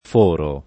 forare v.; foro [
f1ro] — es. con acc. scr.: E neri gli occhi scintillando immoti Fóran dal fondo del pensier le cose [e nn%ri l’l’ 0kki ššintill#ndo imm0ti f1ran dal f1ndo del penSL$r le k0Se] (Carducci) — cfr. foro